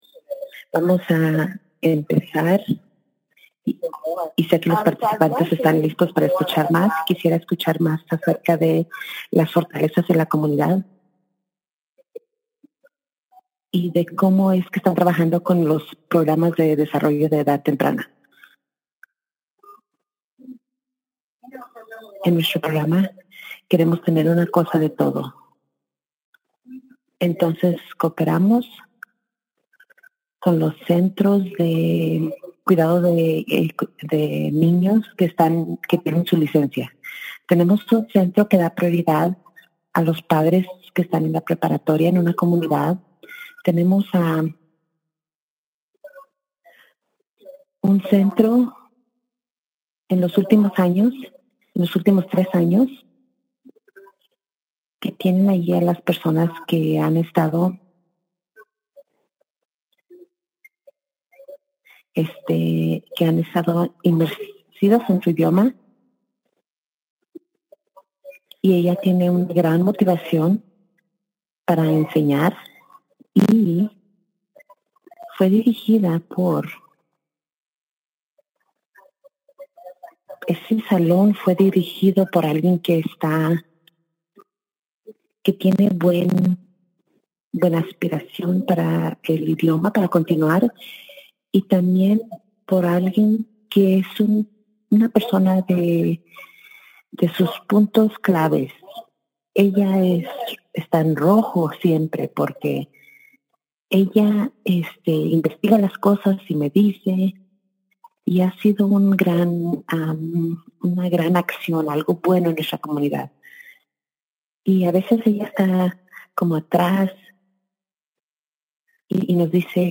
For nearly 20 years, Brazelton Touchpoints Center has worked in partnership with tribal communities to support American Indian/Alaska Native community goals for all children to thrive. This webinar features tribal partners and staff with the Tribal Touchpoints Initiative.